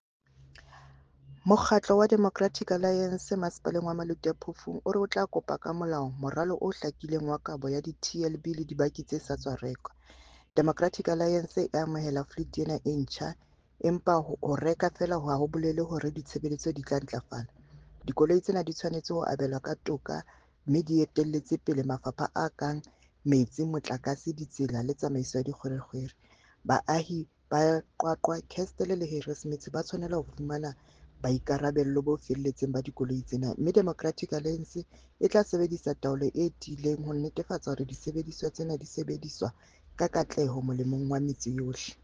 Sesotho soundbite by Cllr Ana Motaung and